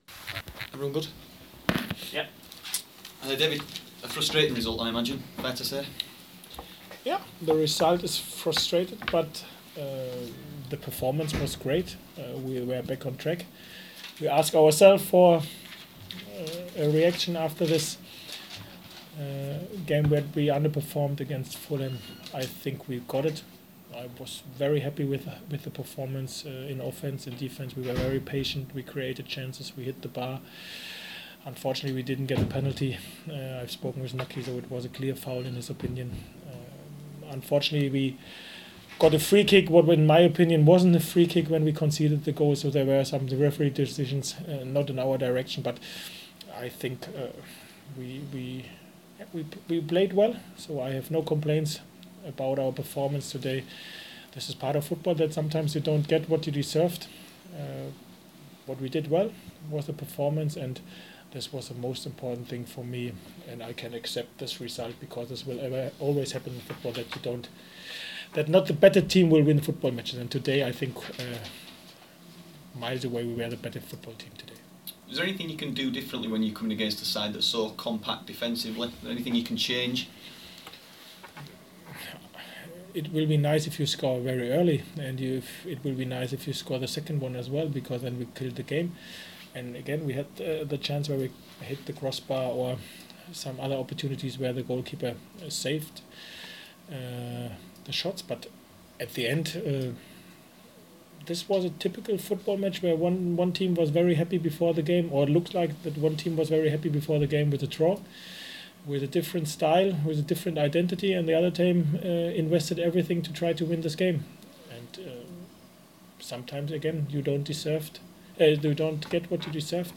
David Wagner speaks following Huddersfield's 1-1 draw with Birmingham